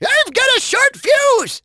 tnt_guy_start_vo_01.wav